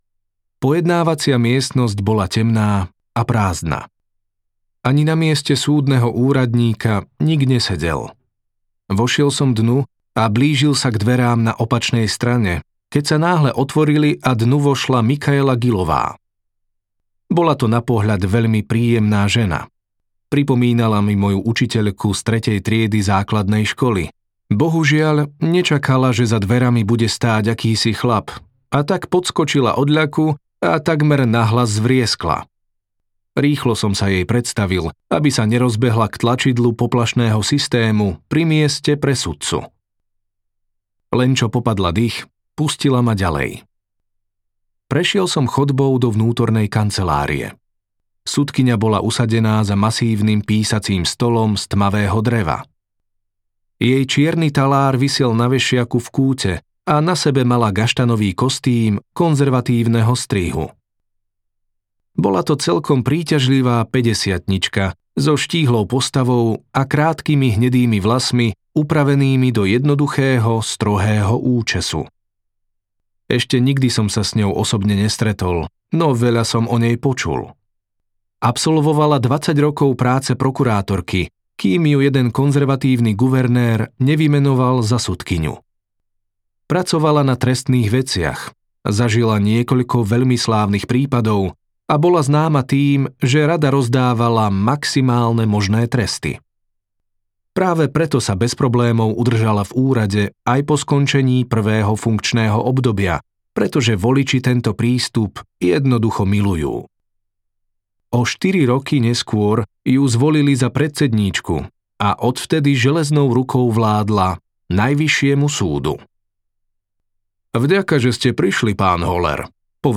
Rozsudok ulice audiokniha
Ukázka z knihy